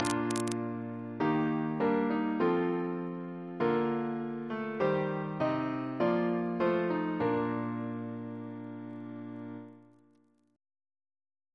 Single chant in B♭ Composer: Sir George Elvey (1816-1893), Organist of St. George's Windsor; Stephen's brother Reference psalters: ACB: 133; OCB: 280; PP/SNCB: 163; RSCM: 176